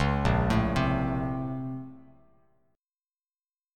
A#mM9 chord